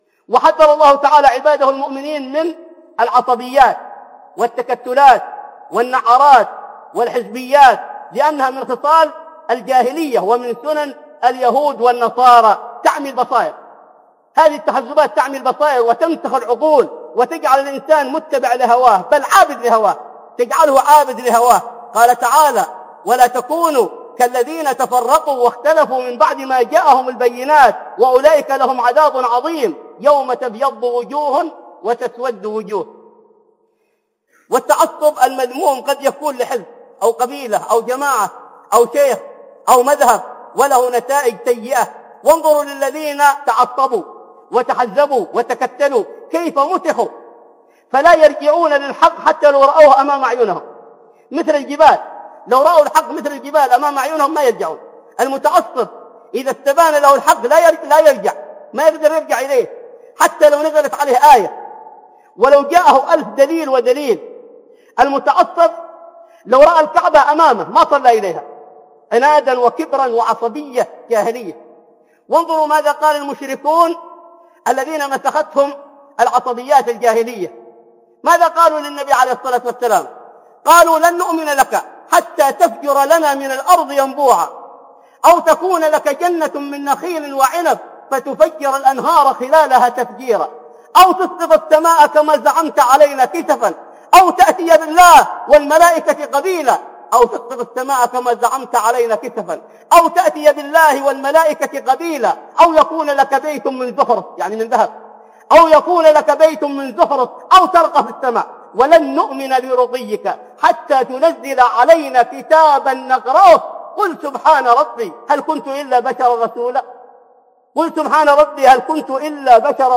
خطب ومحاضرات أخرى